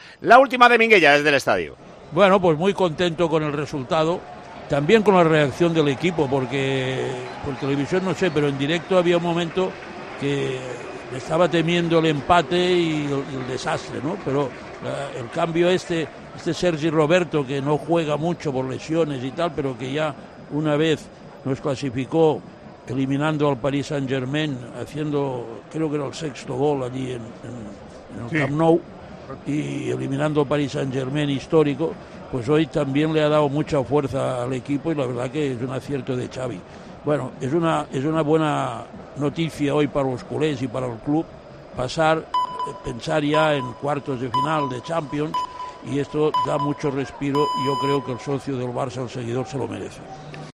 El comentarista de Tiempo de Juego destacó la victoria del Barcelona en los octavos de final de la Champions League tras eliminar al Nápoles.